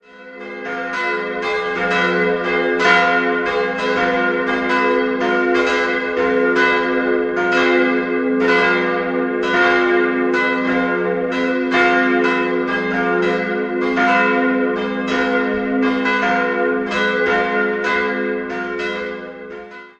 Jahrhundert hinzog. 3-stimmiges F-Moll-Geläute: f'-as'-c'' Alle drei Glocken wurden von Karl Hamm in Regensburg gegossen: die beiden kleineren 1949, ein Jahr später kam die große dazu.